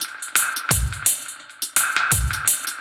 Index of /musicradar/dub-designer-samples/85bpm/Beats
DD_BeatC_85-03.wav